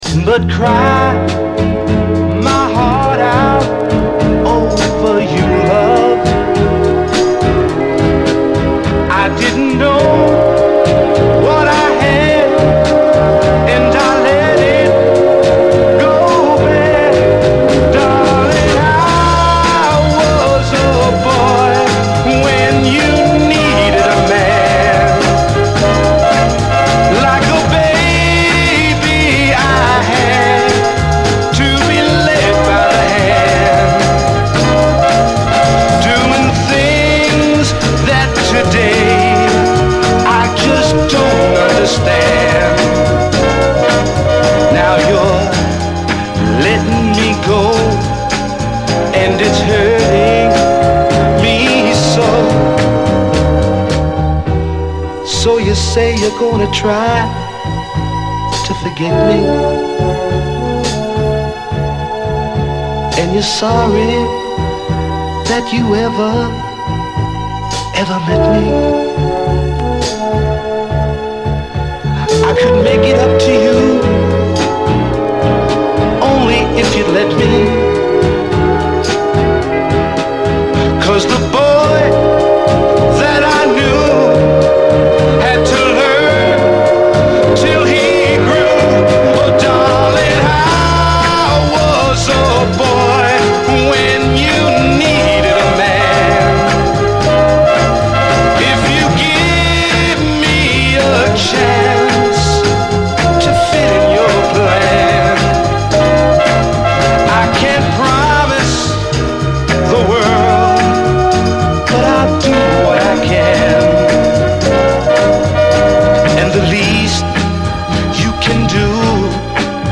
SOUL, 60's SOUL, 60's ROCK, ROCK, 7INCH
69年のマイナー・ブルーアイド・ソウル〜ソフト・ロック！
切なくこみ上げるメロディーが美しいです！